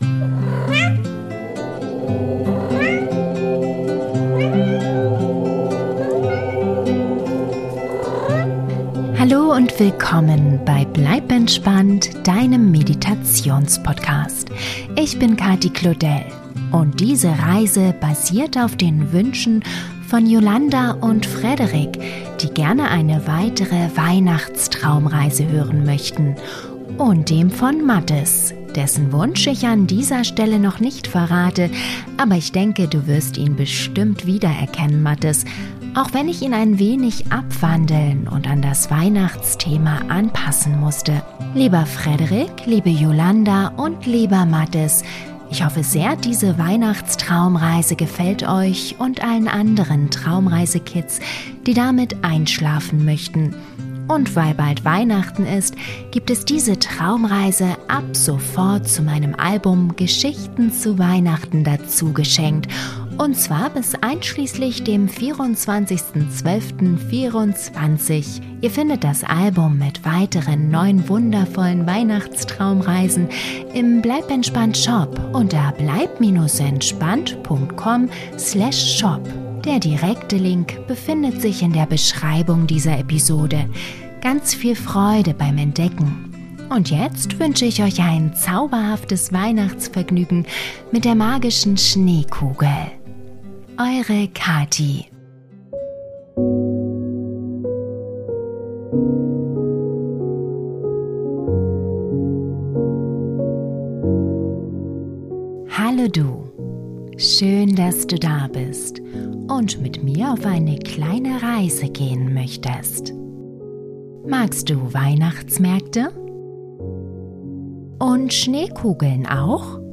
Traumreise für Kinder zum Einschlafen - Die magische Schneekugel - Weihnachtsmarkt Geschichte ~ Bleib entspannt!